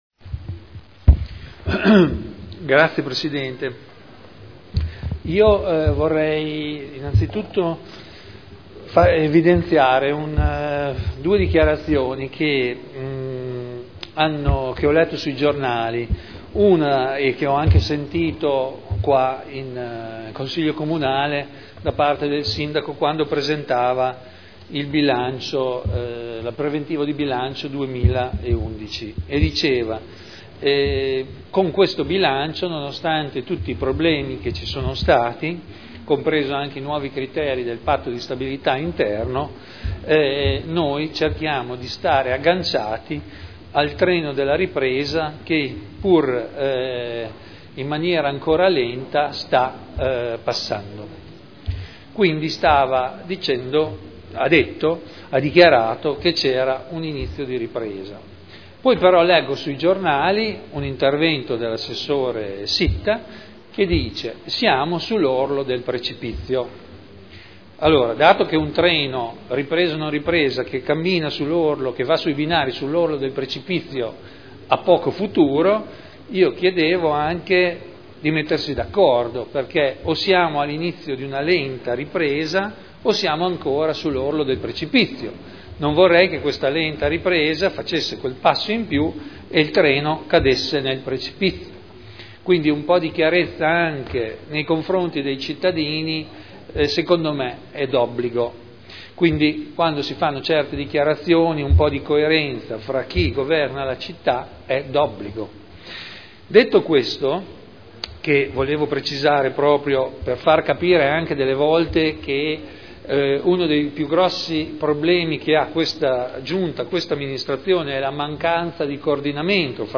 Seduta del 28/04/2011. Dibattito su Delibera: Rendiconto della gestione del Comune di Modena per l’esercizio 2010 – Approvazione (Commissione consiliare del 21 aprile 2011)